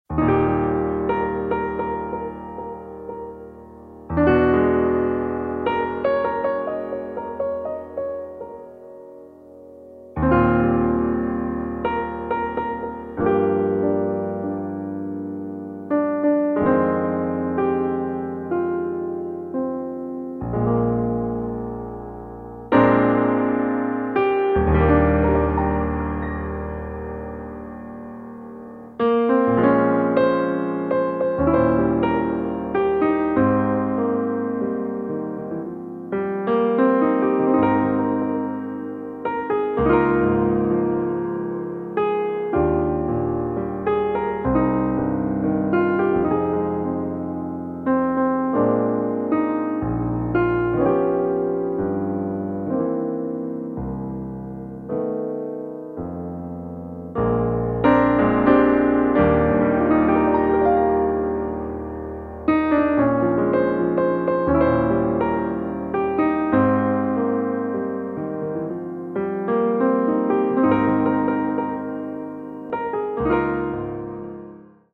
Solo piano arrangement